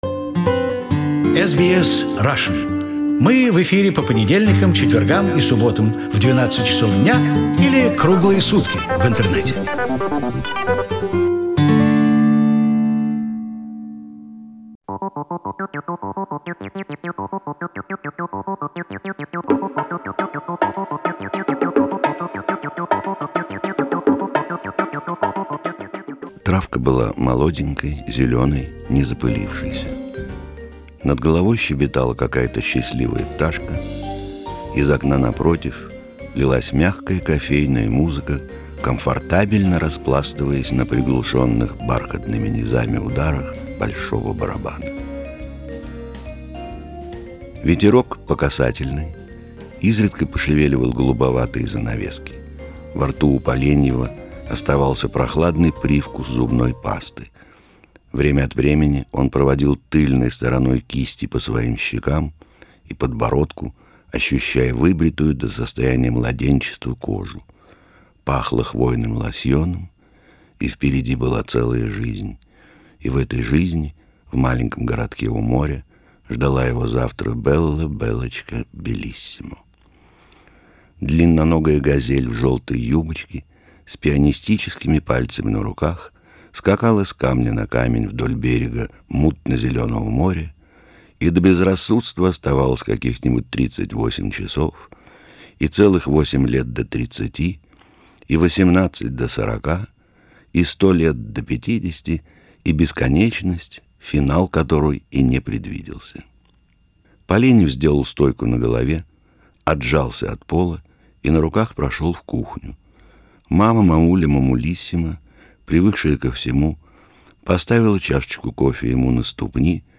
During one of his visits, in 2002, we taped Arkady Arkanov reading three of his stories which we produced and later aired several times. And as often with Arkanov, they are far from just funny stories, in fact, they quite sad and philosophical miniatures.